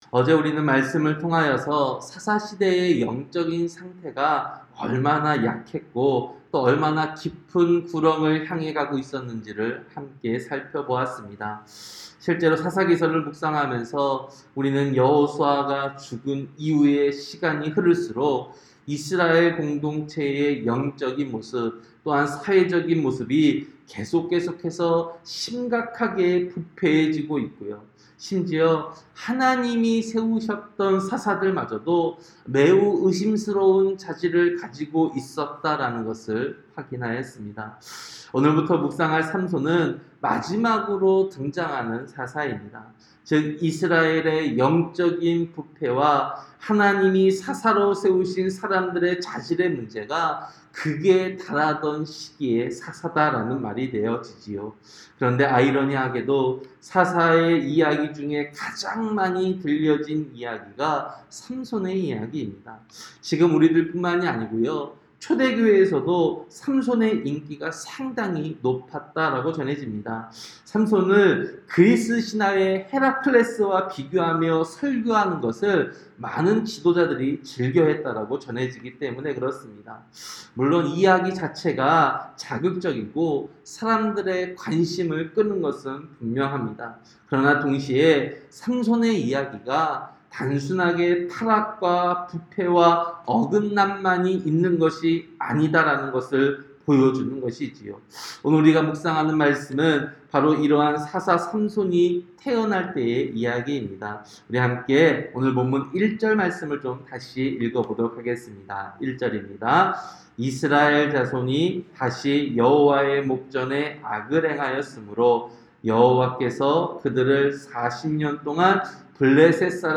새벽설교-사사기 13장